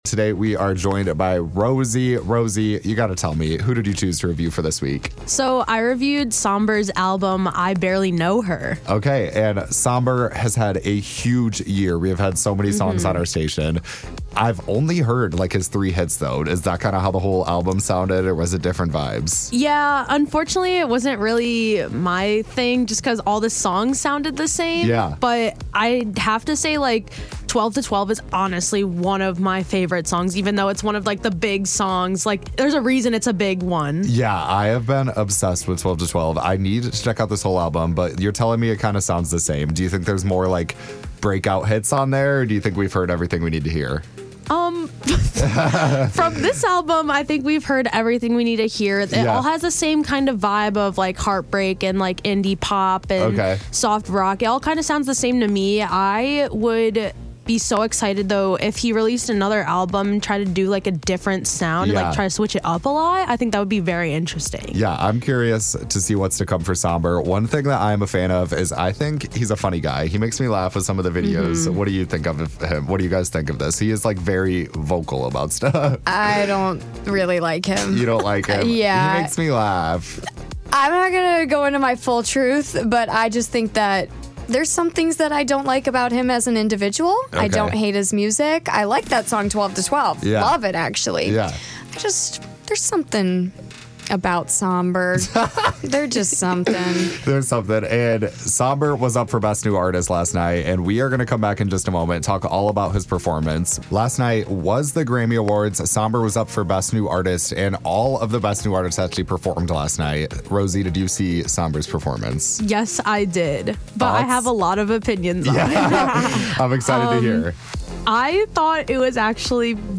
I Barely Know Her- Sombr Album Review